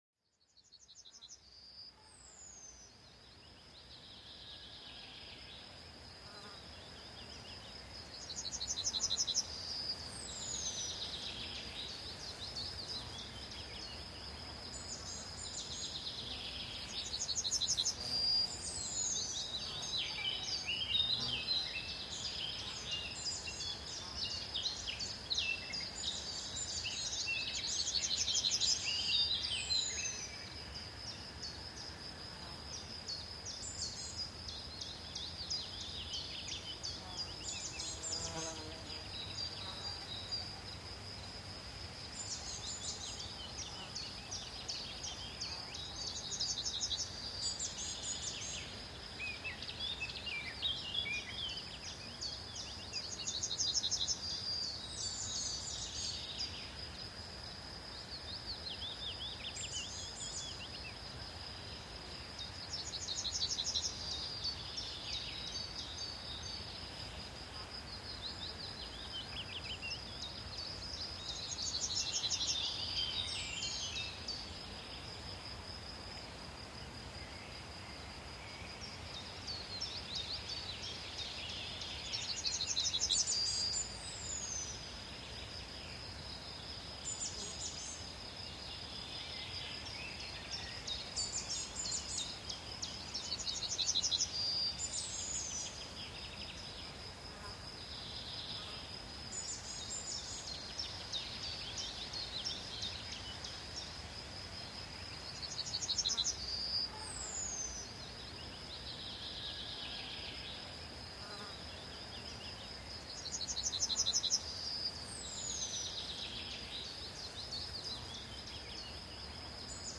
Escucha los relajantes sonidos de los pájaros para relajarte y conciliar el sueño fácilmente